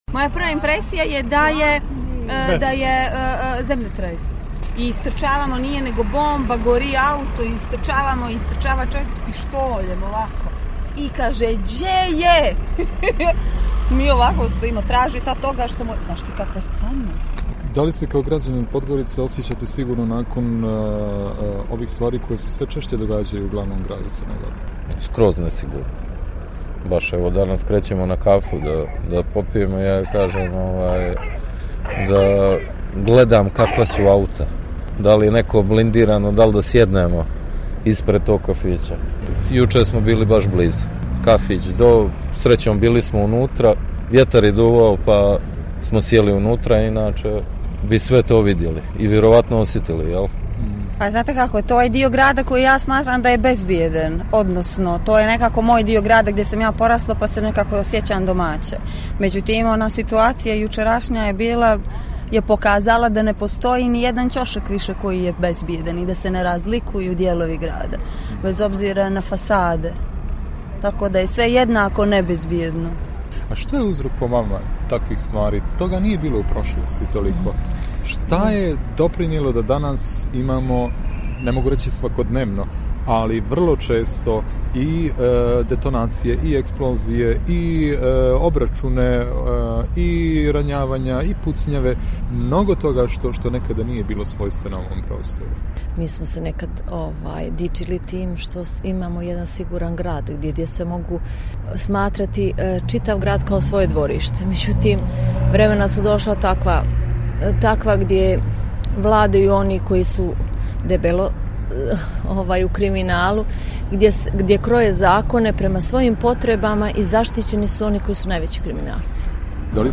govorili su i neki od očevidaca kriminalnog akta